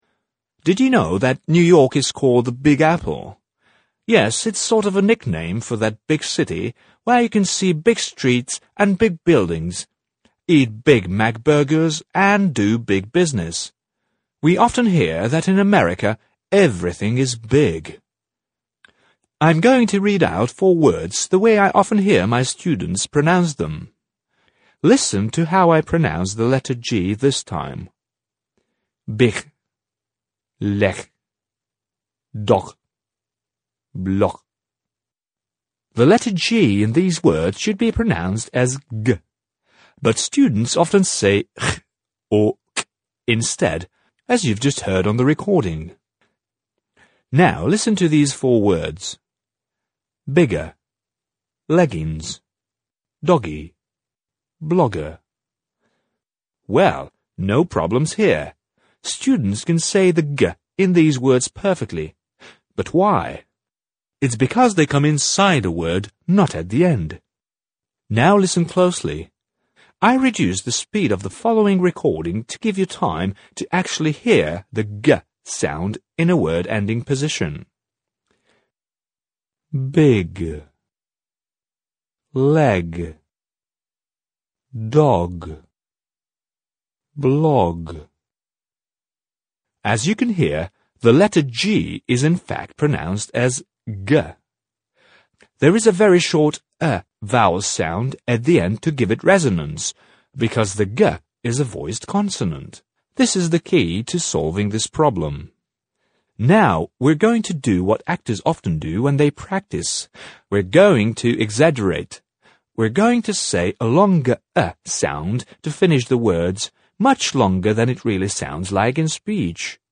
I’m going to read out four words the way I often hear my students pronounce them.
I reduced the speed of the following recording to give you time to actually hear the /g/ sound in a word-ending position:
There is a very short /ə/ vowel sound at the end to give it resonance, because the /g/ is a voiced consonant.
Practise them in this order, after the beep.